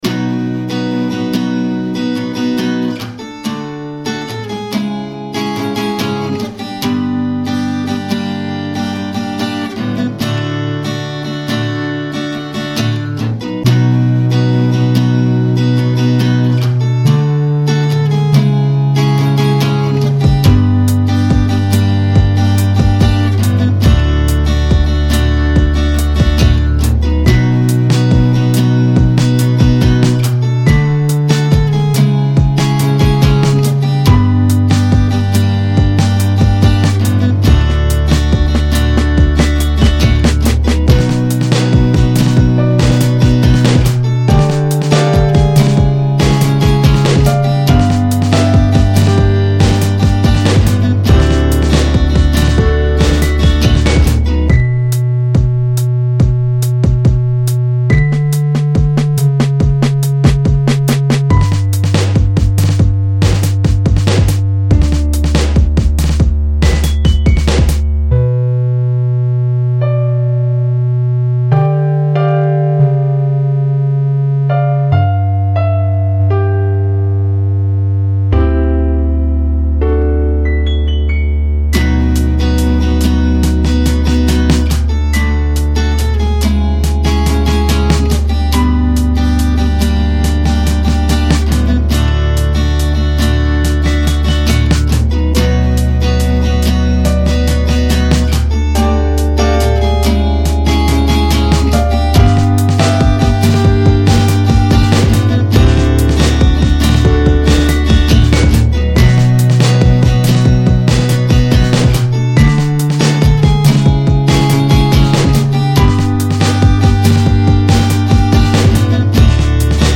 Gitarrenmusik mit elektronschem Klavier als Hörgeschmack.